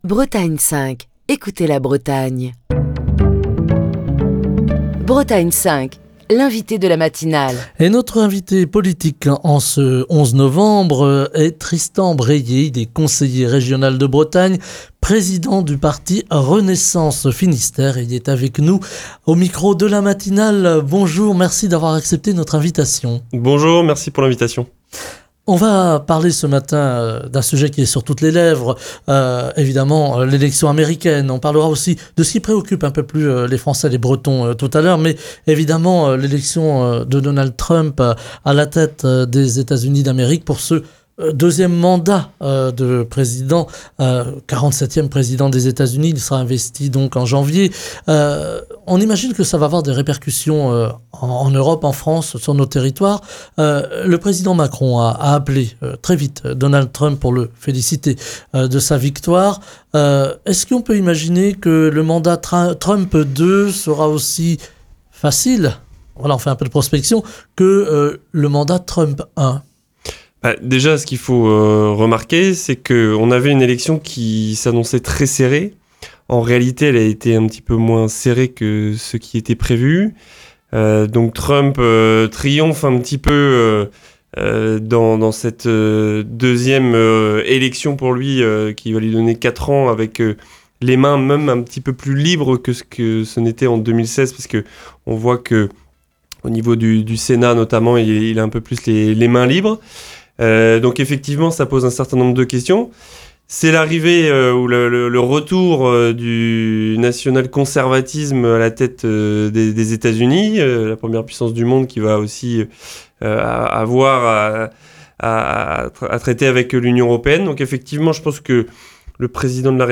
Tristan Bréhier, conseiller régional de Bretagne, président du parti Renaissance Finistère, est l'invité politique de la matinale de Bretagne 5 en ce lundi. Tristan Bréhier fait part de sa lecture politique des élections américaines qui ont vu la victoire de Donald Trump, et plus largement des Républicains les plus conservateurs.